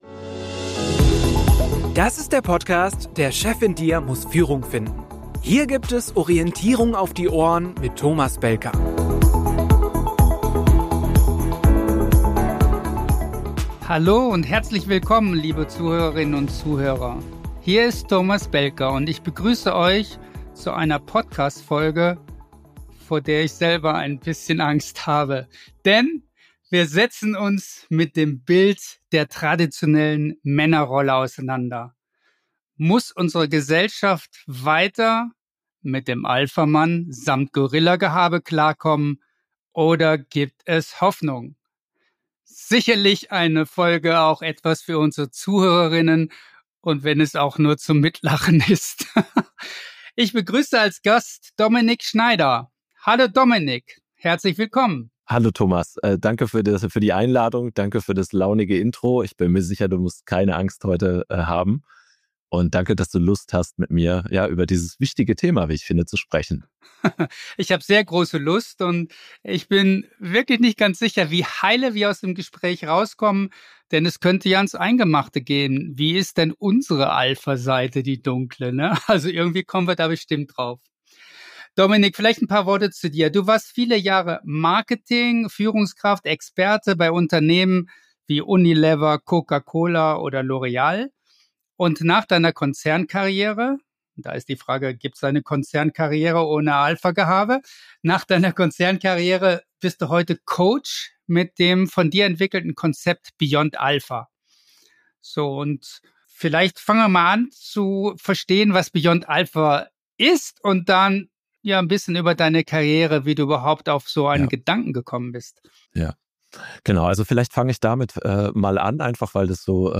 – Darüber diskutieren die beiden in diesem inspirierenden, anstoßenden und motivierenden Talk, der Männer und Frauen weiterbringt.